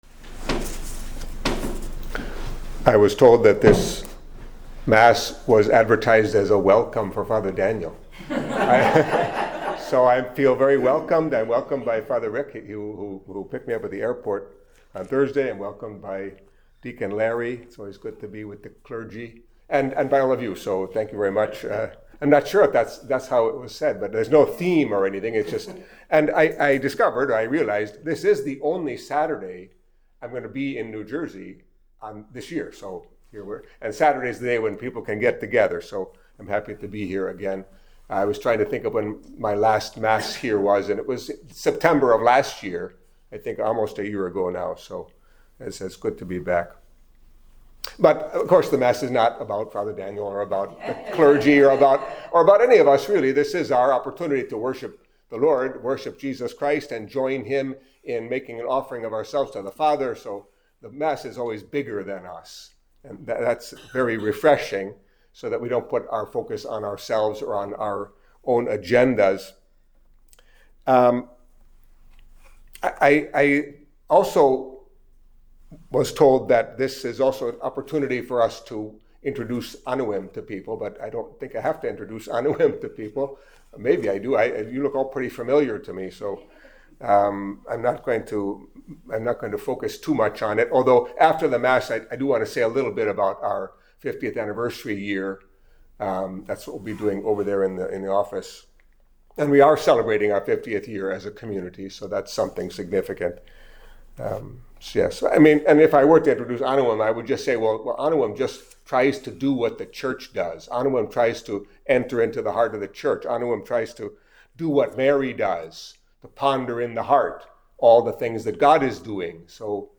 Catholic Mass homily for Saturday of the Twenty-Second Week in Ordinary Time